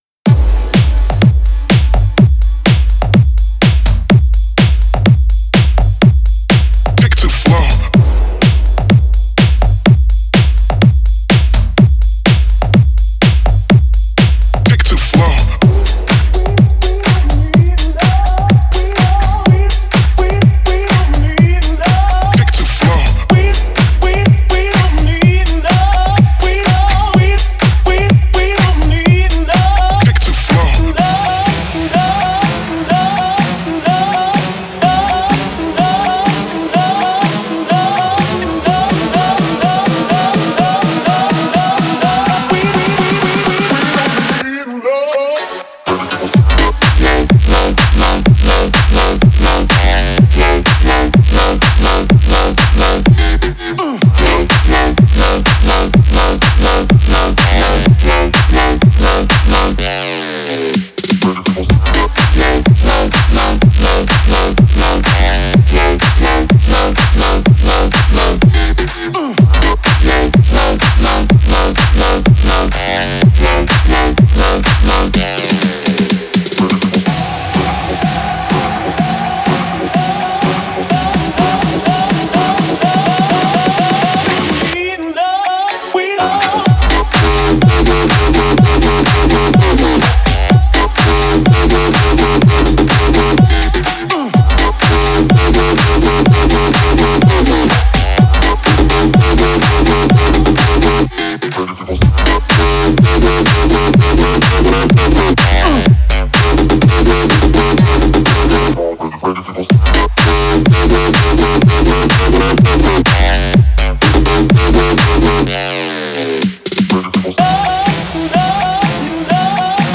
basshouse